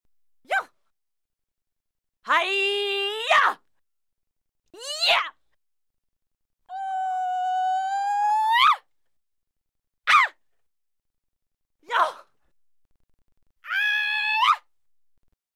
Женщина-каратистка издает звуки ртом при ударах